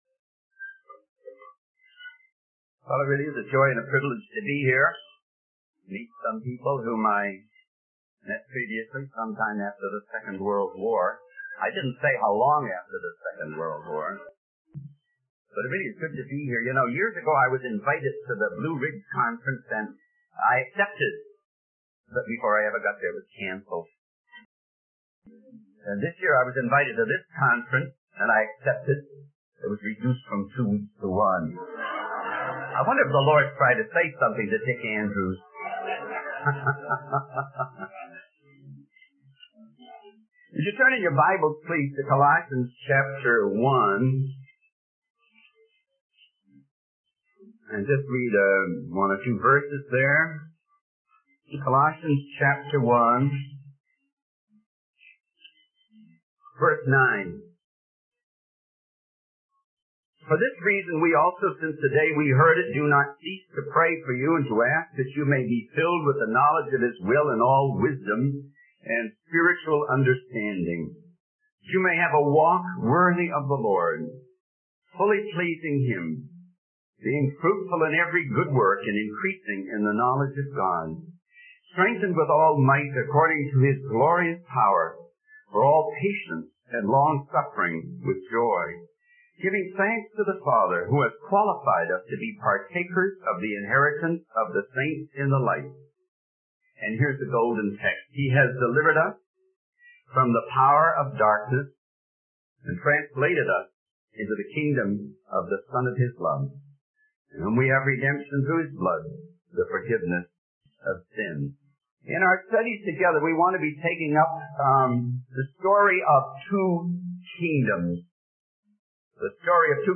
In this sermon, the preacher discusses the emptiness of the world and how it fails to satisfy the human heart. He references a story about a young entertainer who had achieved fame and success but still felt empty inside.